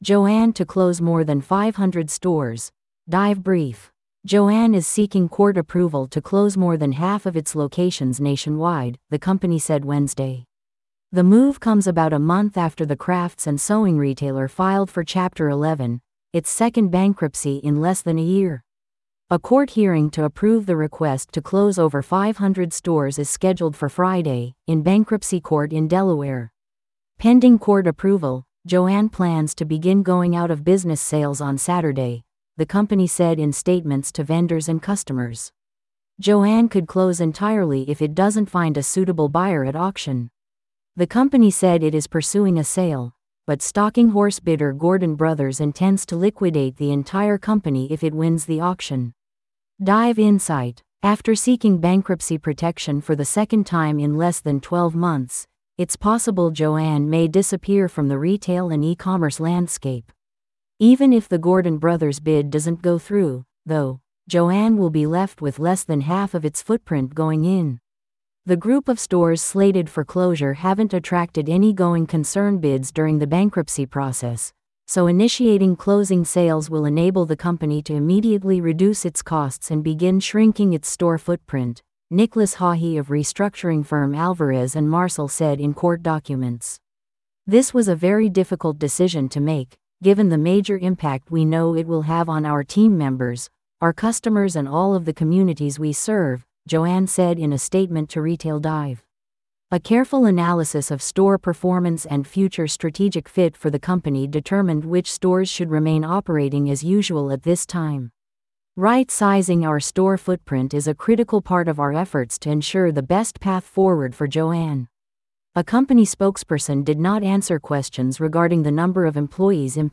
This audio is auto-generated. Please let us know if you have feedback.